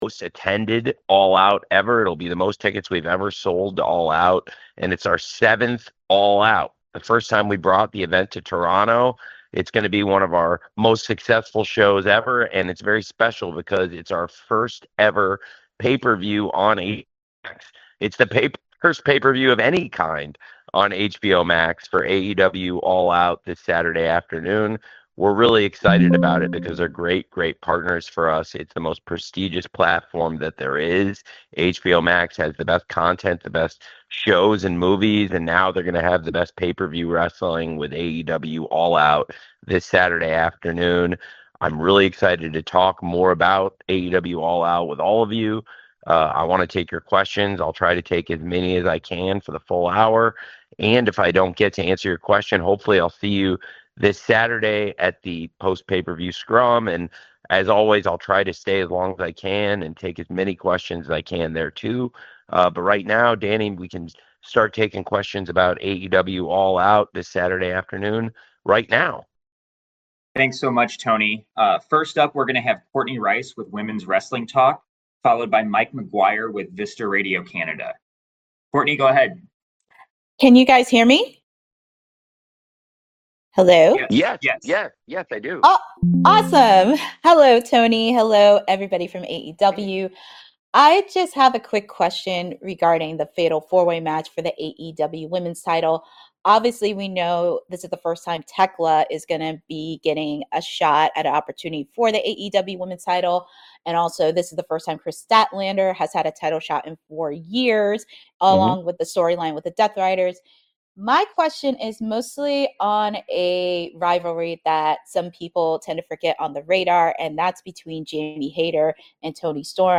AEW All Out 2025 Media Call with Tony Khan
Tony Khan speaks with the media ahead of AEW All Out 2025 taking place on Saturday, September 20, 2025 at the .Scotiabank Arena in Toronto, Ontario, Canada.